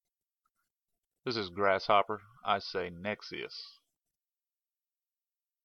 [Ogg] Pronouncing the Name